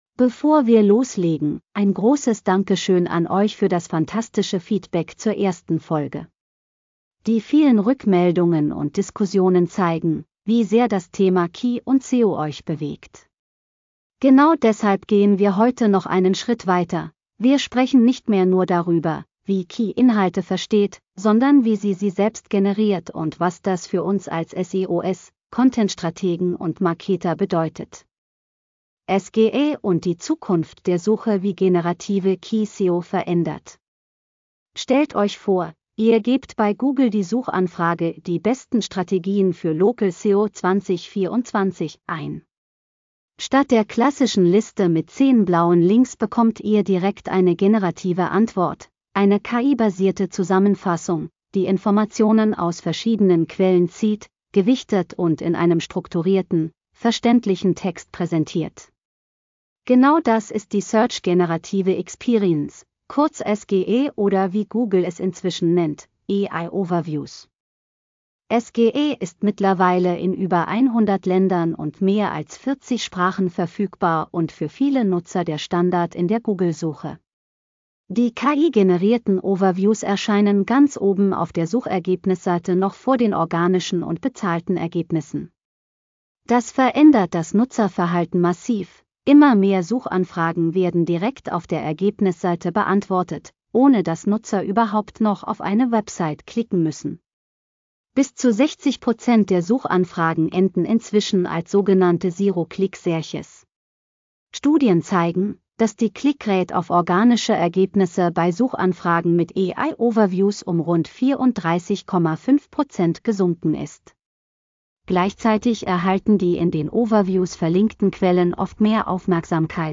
unserer Stimme nicht immer korrekt ausgesprochen.